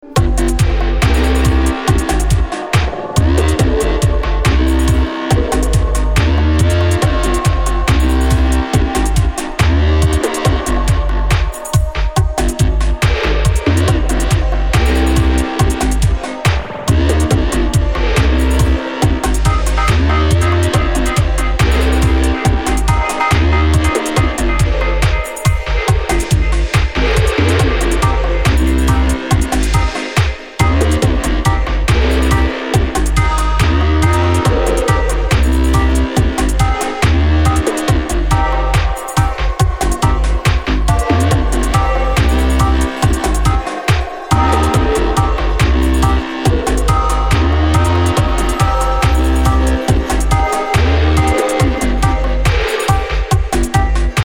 dubby side of bass music scene